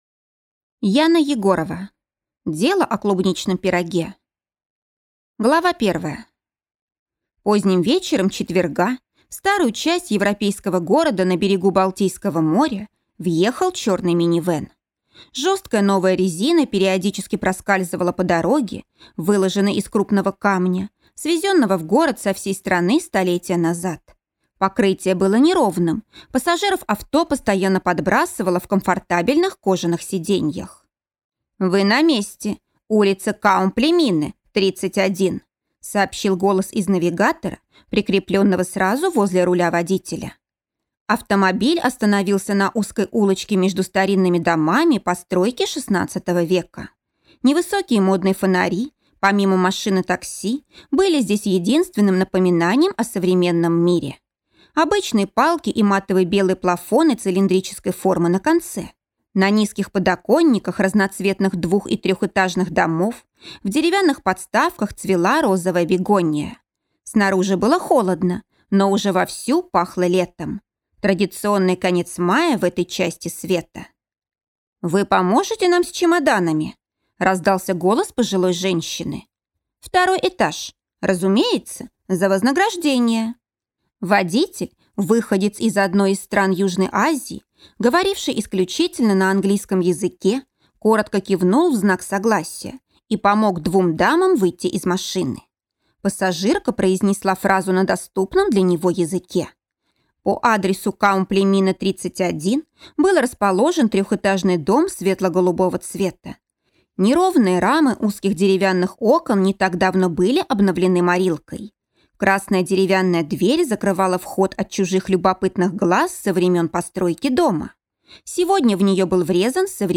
Аудиокнига Эрнестина Вольф. Дело о клубничном пироге | Библиотека аудиокниг